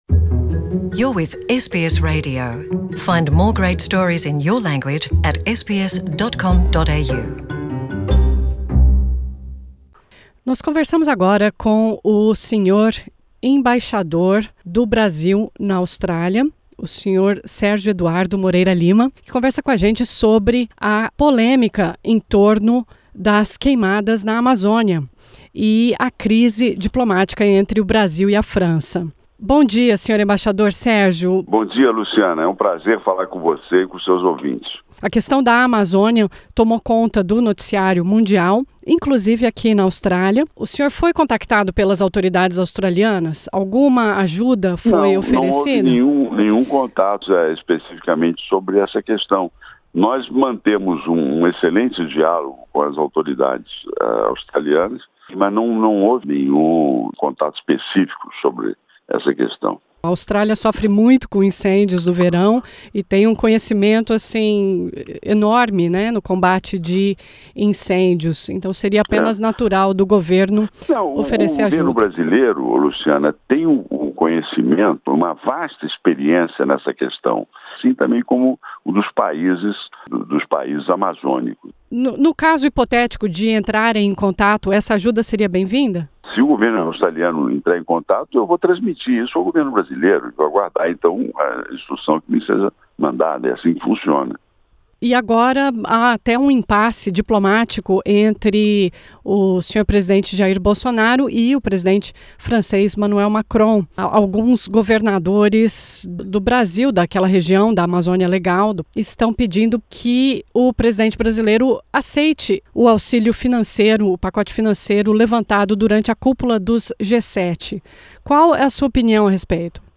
Em uma entrevista especial, o Embaixador do Brasil na Austrália, Sérgio Eduardo Moreira Lima, fala sobre os incêndios na Amazônia, o comportamento do governo francês e a cobertura jornalística na Austrália; e fala também do visto Work and Holiday para o Brasil; do voo Sydney-Santiago-Brasília e do processo de nomeação do próximo cônsul honorário de Queensland, que vai substituir o ex-cônsul Valmor Morais.
Sérgio Eduardo Moreira Lima e Roger Frankel, cônsul honorário de Melbourne, durante entrevista na SBS Source: LF/SBSPortuguese